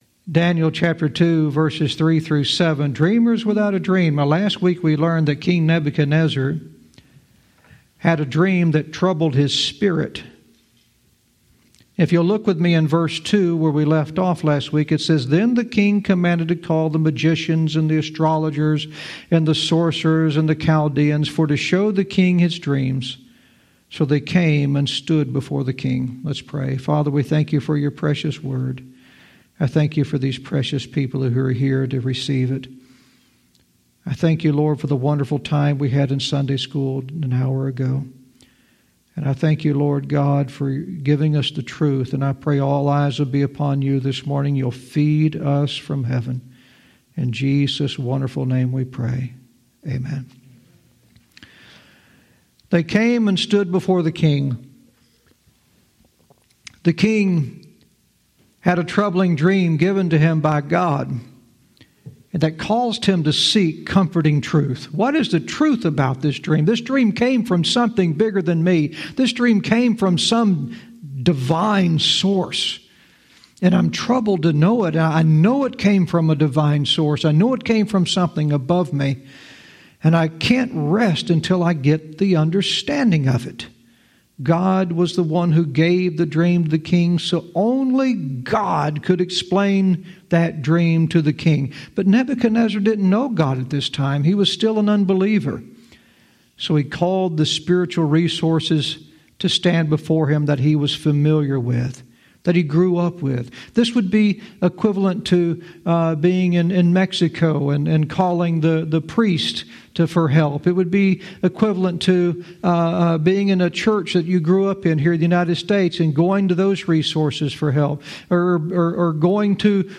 Verse by verse teaching - Daniel 2:3-7 "Dreamers Without a Dream"